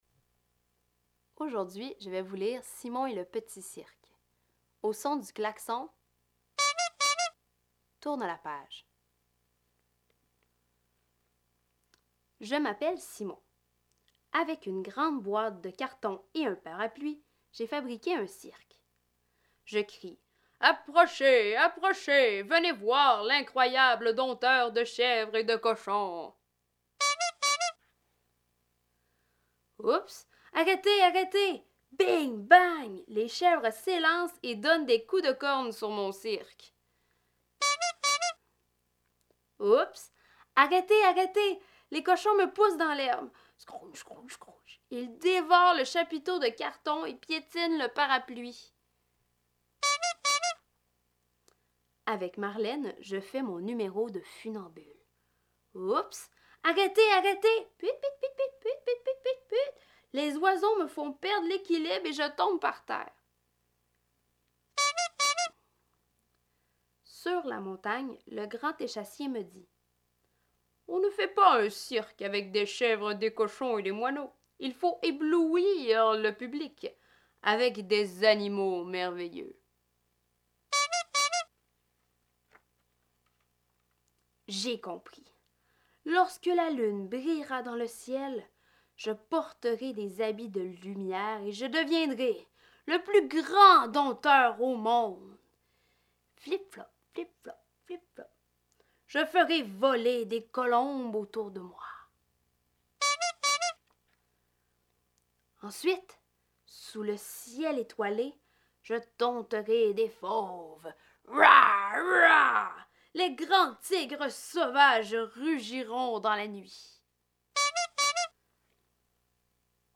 Durant le mois de novembre, l’un des cinq ateliers de la semaine était l’écoute d’un livre, préalablement enregistré, en équipe de trois ou de quatre.
• Le responsable du livre devait tourner les pages au son du klaxon et il pouvait essayer de suivre les mots avec son doigt.